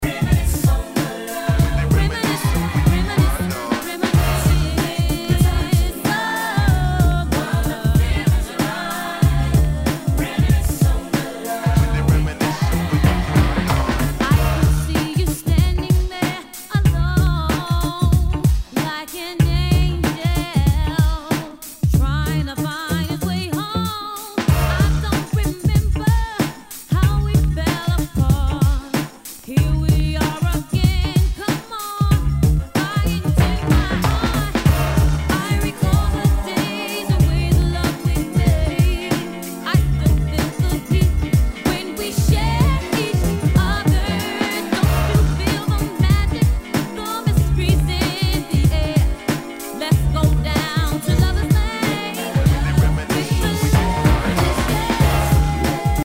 HIPHOP/R&B